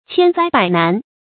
千災百難 注音： ㄑㄧㄢ ㄗㄞ ㄅㄞˇ ㄣㄢˊ 讀音讀法： 意思解釋： 多災多難。